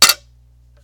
mob_attack.ogg